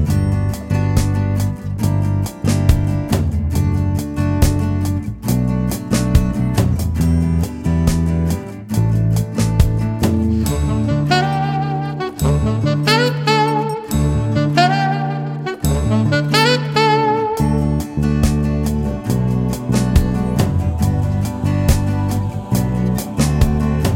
no piano Pop (1980s) 4:16 Buy £1.50